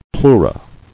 [pleu·ra]